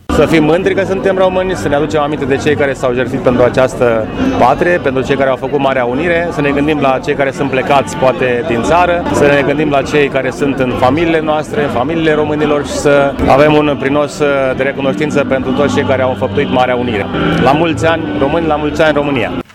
La manifestarea din Piața Sfatului au participat oficialități locale și județene, printre care și primarul Brașovului, George Scripcaru: